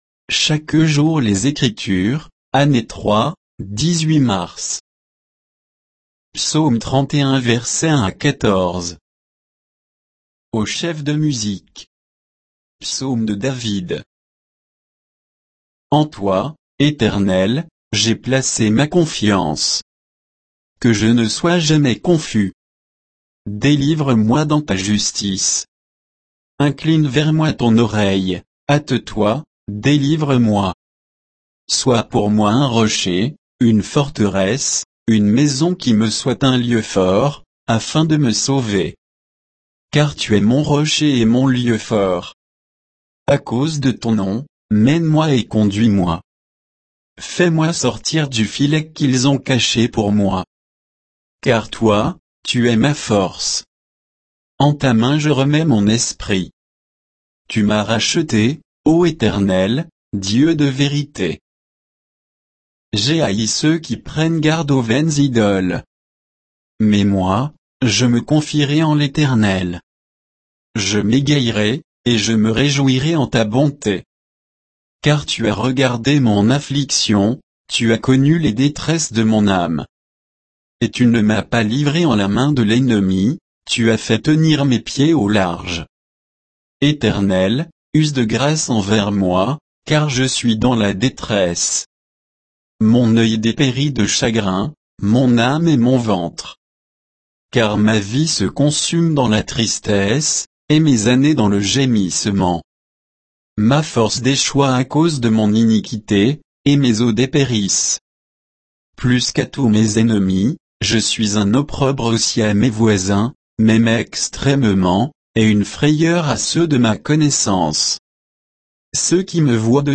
Méditation quoditienne de Chaque jour les Écritures sur Psaume 31